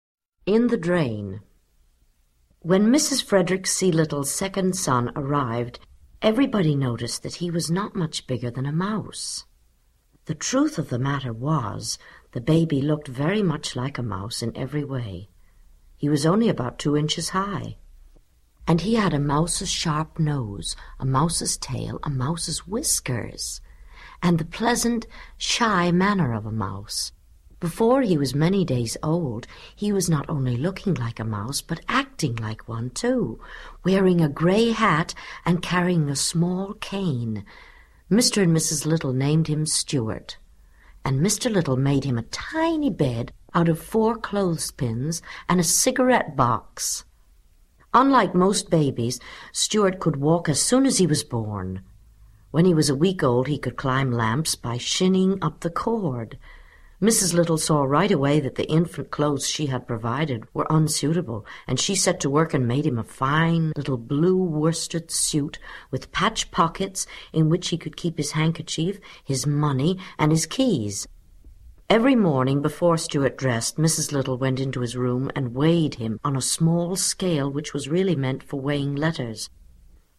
在线英语听力室精灵鼠小弟 第1期:像老鼠的婴儿的听力文件下载, 《精灵鼠小弟》是双语有声读物下面的子栏目，是学习英语，提高英语成绩的极好素材。本书是美国作家怀特(1899—1985)所著的三部被誉为“二十世纪读者最多、最受爱戴的童话”之一。